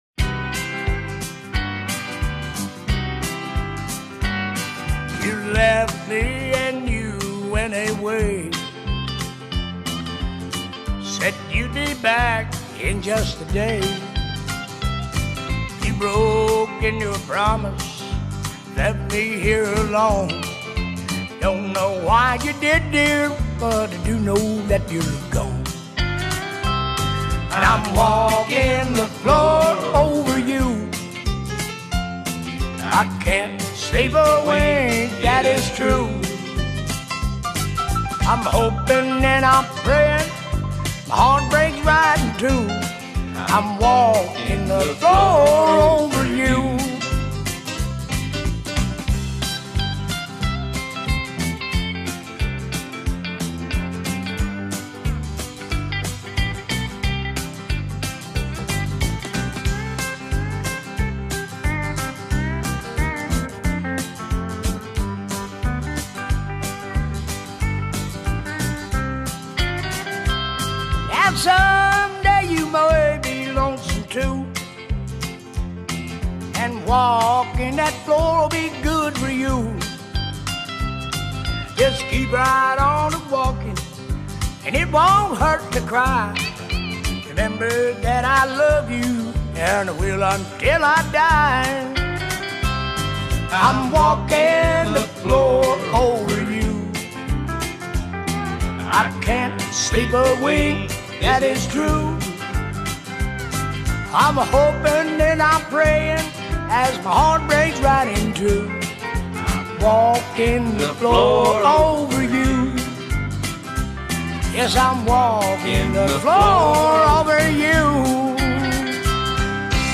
This is REAL traditional Country!